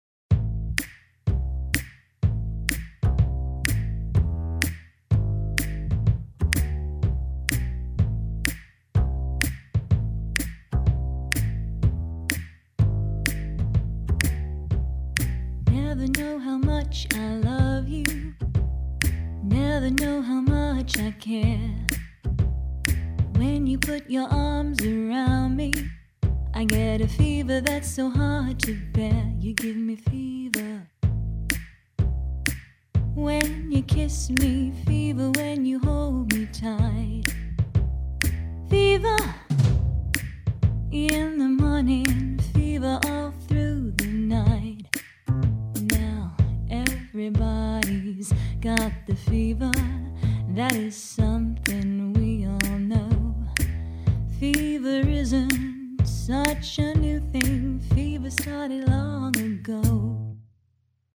Demo track